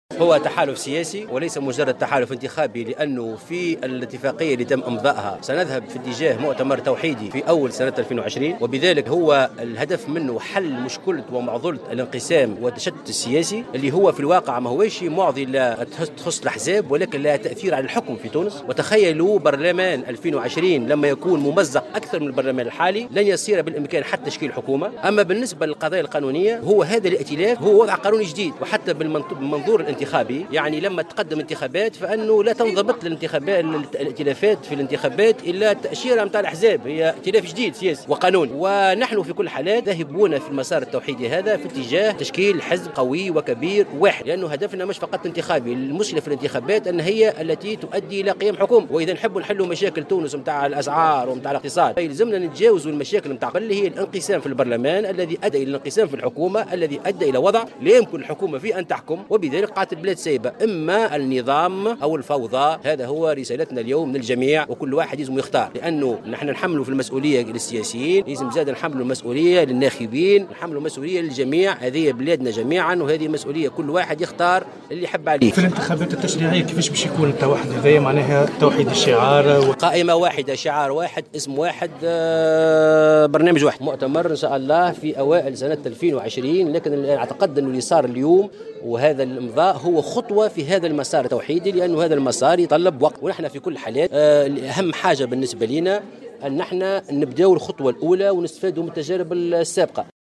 وأضاف مرزوق في تصريح للجوهرة أف أم خلال ندوة صحفية انعقدت اليوم الثلاثاء أن التحالف بين حزب مشروع تونس وحركة نداء تونس (شق الحمامات) هو تحالف سياسي هدفه تجاوز معضلة الإنقسام والتشتت السياسي وفق تعبيره .وللإشارة فإن حركتي مشروع تونس ونداء تونس وقّعتا اليوم الثلاثاء 11 جوان 2019 اتفاق تحالف ينصّ على عقد مؤتمر توحيدي في مطلع 2020، وتأسيس مسار توحيدي بين الحزبين.